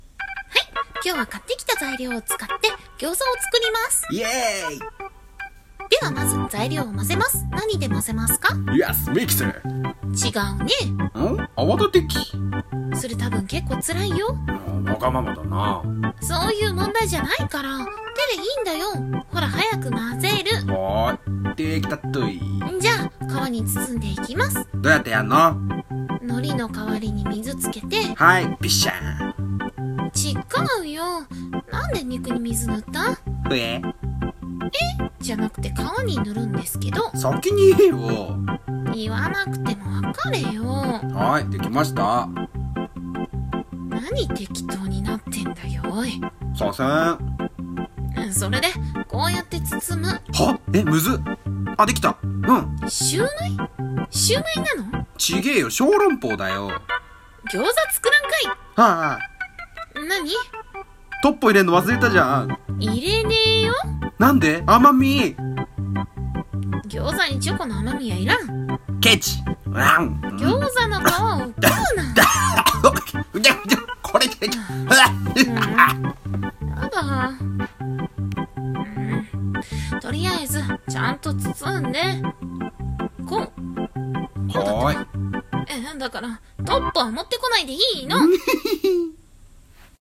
【声劇】アホな彼氏と餃子作り